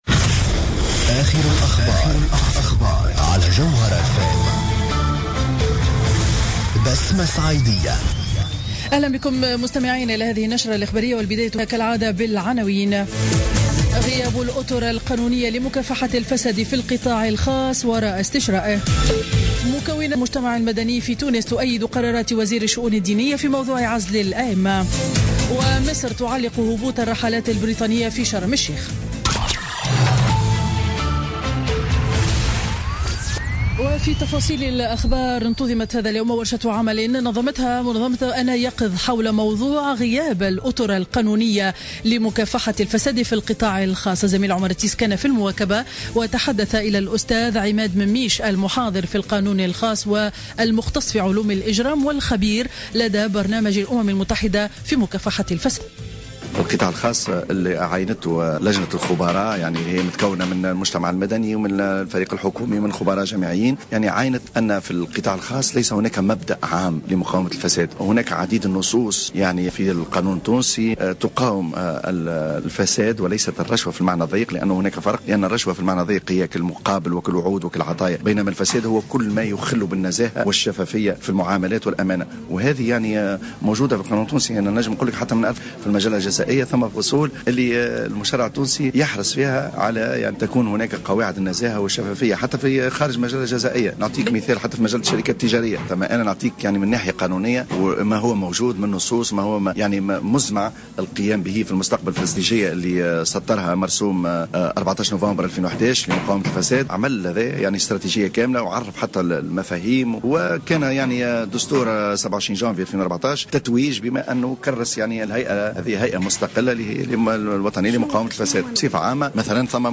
نشرة أخبار منتصف النهار ليوم الجمعة 06 نوفمبر 2015